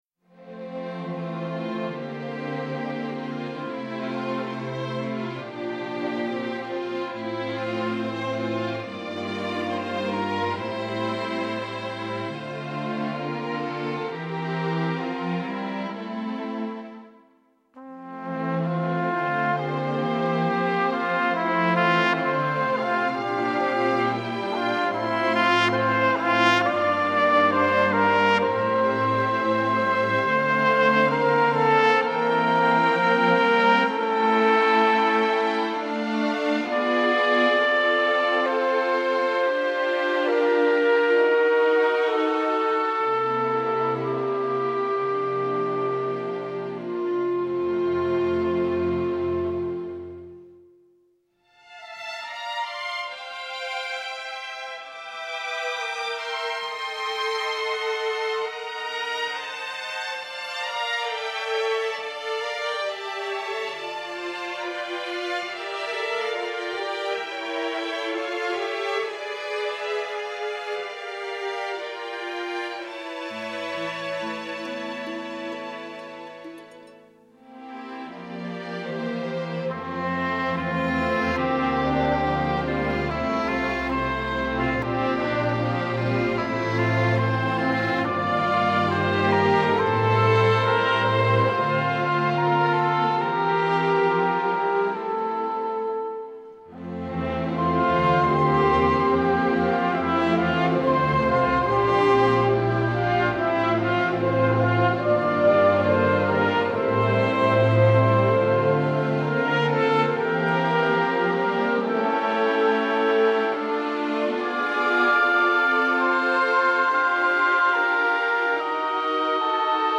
studio, Moscow